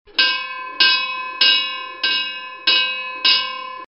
Звук тревожного колокола при пожаре